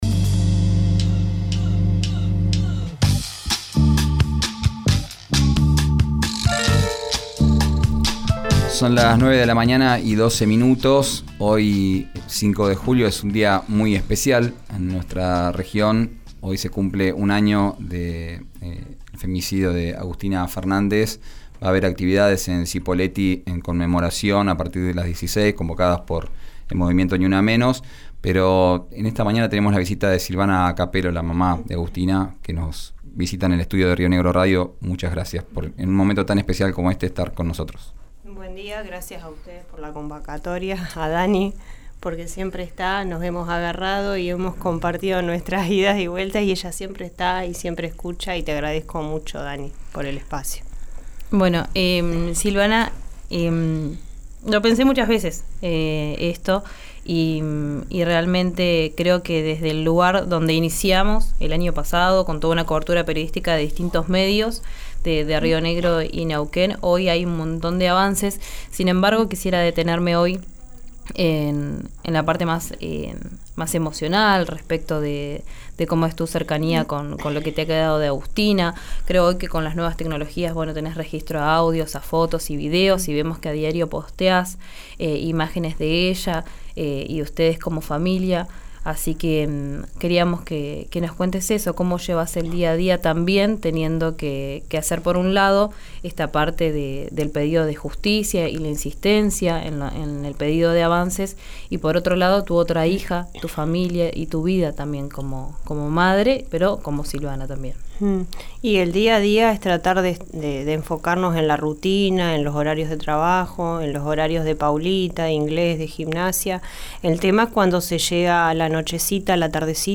en los estudios de la radio